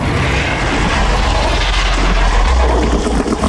File:Mantleclaw roar.ogg